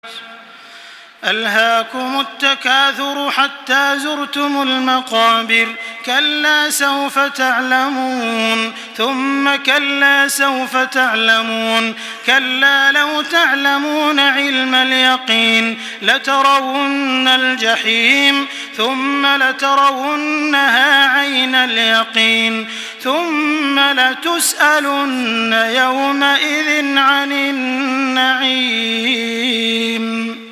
سورة التكاثر MP3 بصوت تراويح الحرم المكي 1425 برواية حفص
مرتل